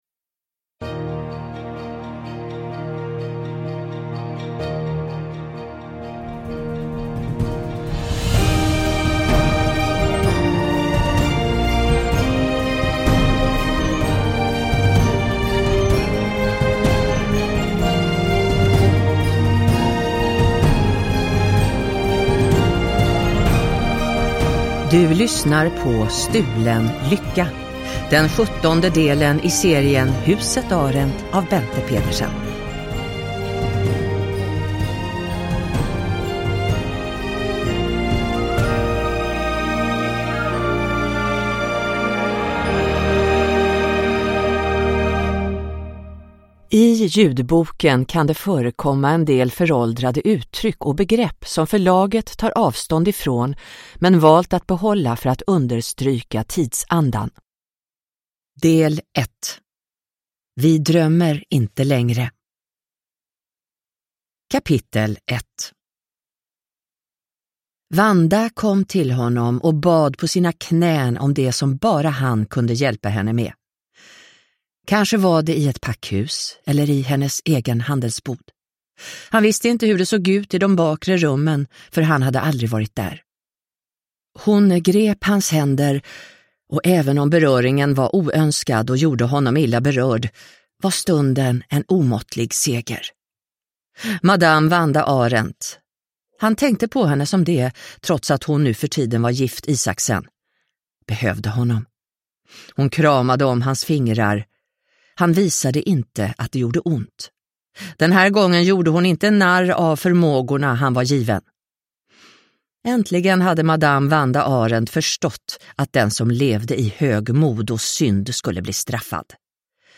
Stulen lycka – Ljudbok – Laddas ner